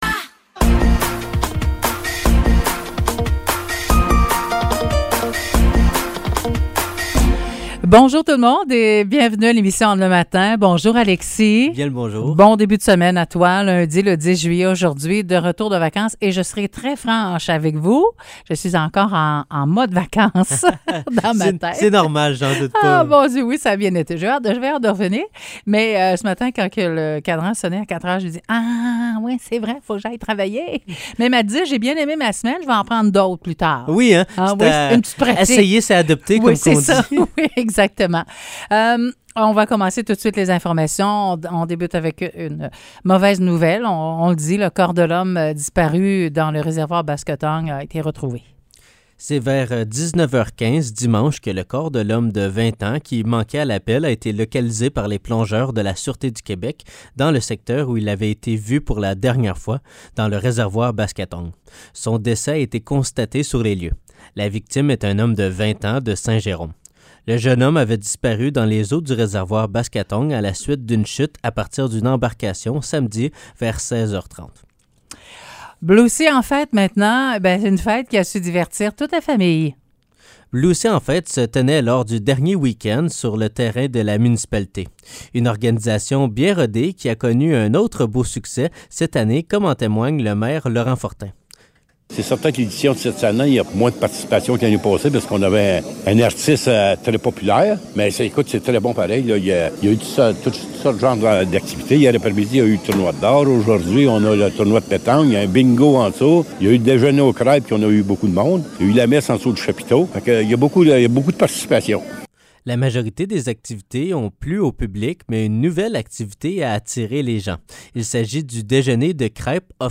Nouvelles locales - 10 juillet 2023 - 9 h